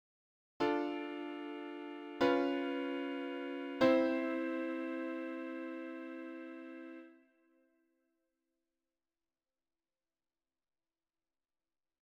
Tritone substitution ii–II–I in C
Play turnaround with a tritone substitution.
Tritone_substitution_ii-subV-I.ogg.mp3